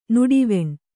♪ nuḍiveṇ